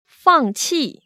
[fàngqì] 팡치